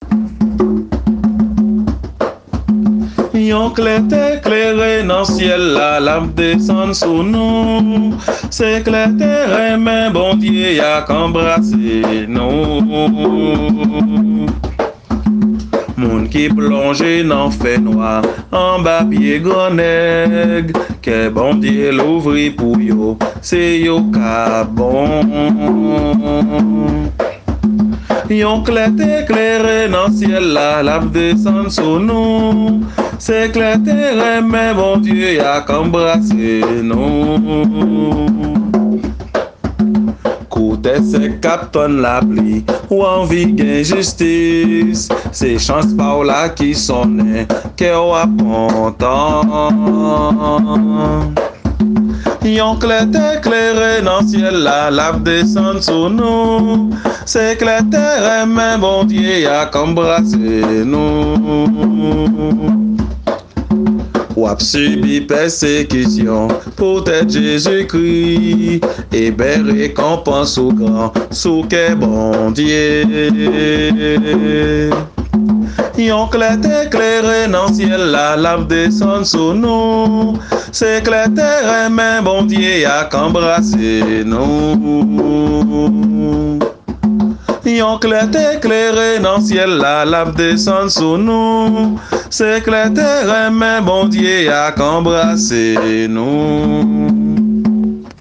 Kantik Kréyòl